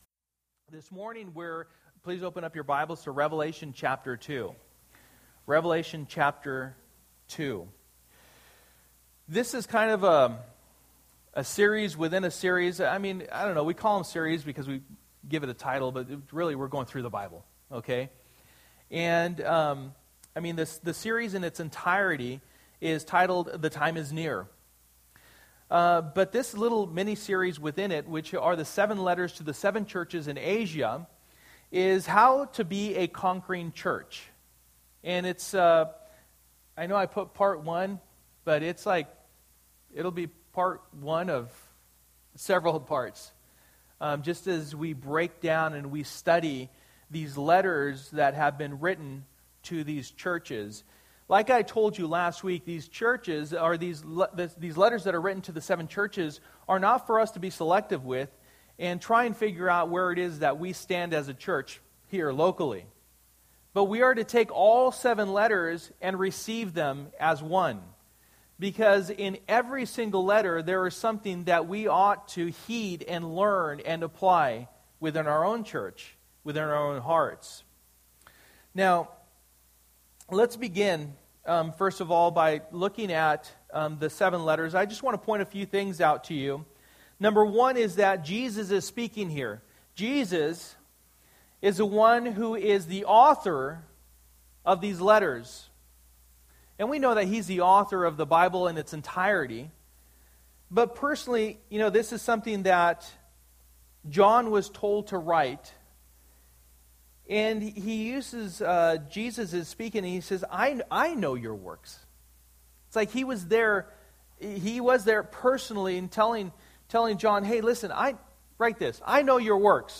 For the Time is Near Passage: Revelation 2:1-7 Service: Sunday Morning